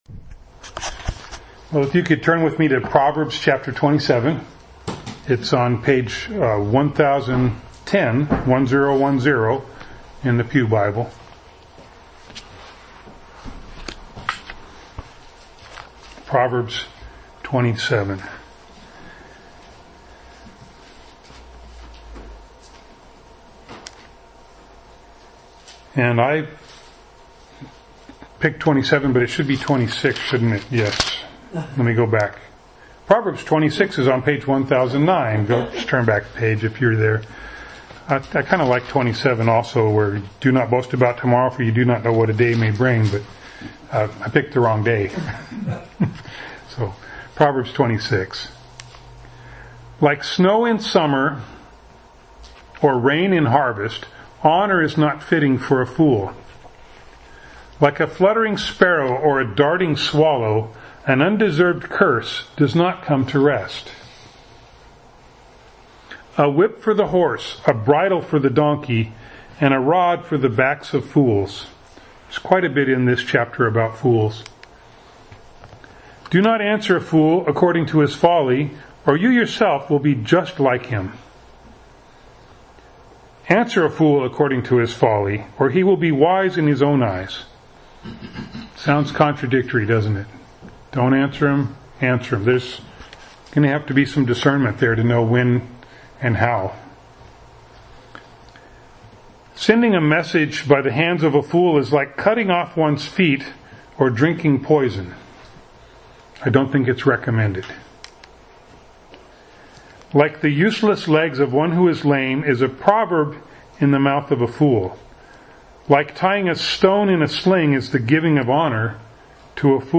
James Service Type: Sunday Morning Preacher